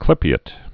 (klĭpē-ĭt) also clyp·e·at·ed (-ātĭd)